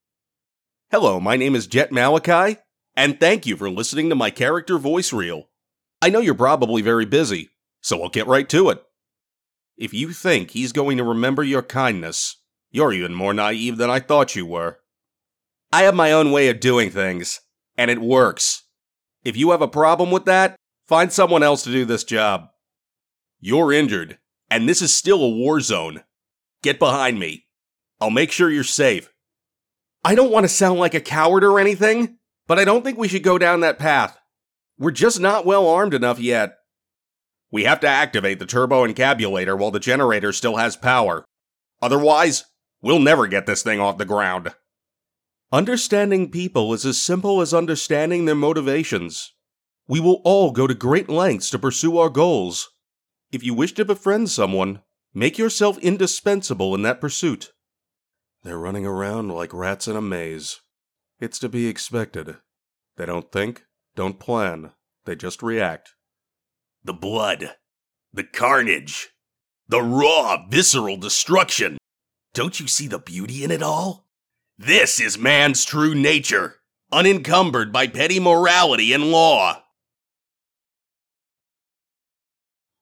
I can alter my voice to suit many tones and many characters, from booming announcements to the deep and dark.
Character Voice Reel